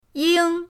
ying1.mp3